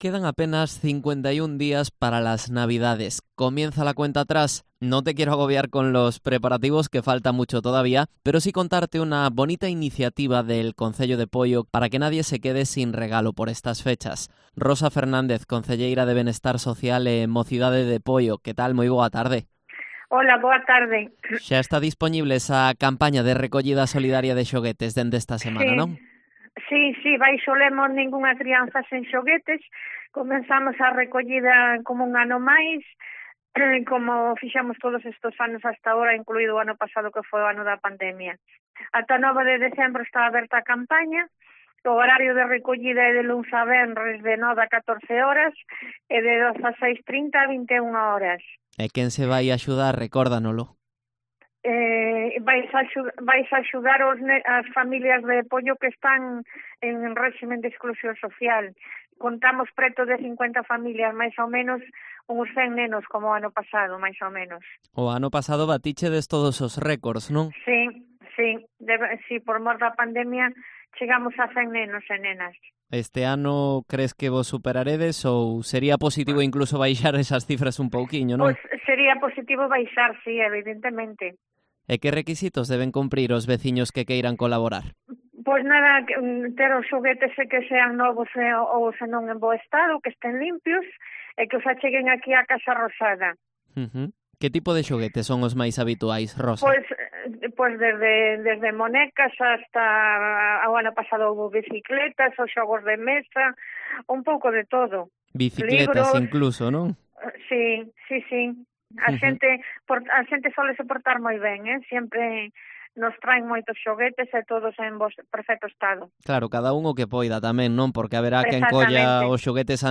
Entrevista a Rosa Fernández, concelleira de Benestar Social e Mocidade de Poio
AUDIO: Entrevista a Rosa Fernández, concelleira de Benestar Social e Mocidade de Poio